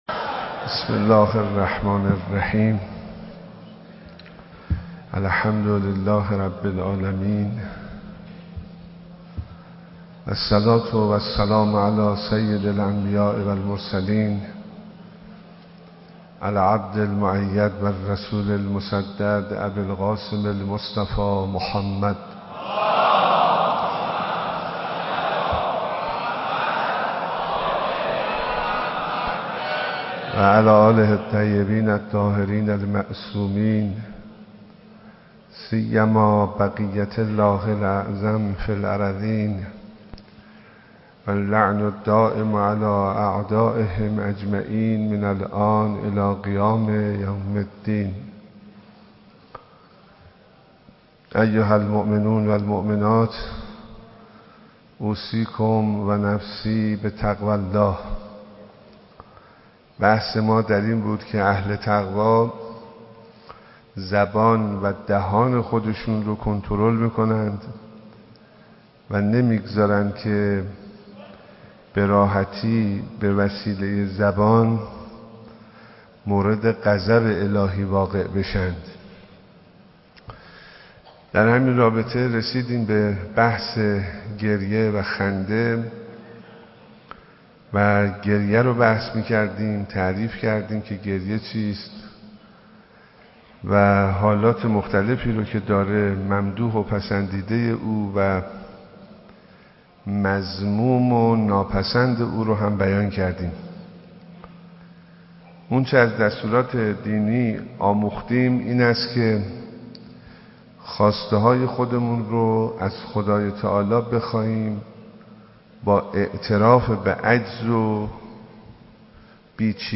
خطبه های نماز جمعه 96/12/18 | امام جمعه کرج